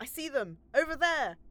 Barklines Combat VA